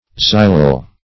Xylol \Xy"lol\, n. [Xylo- + L. oleum oil.] (Chem.)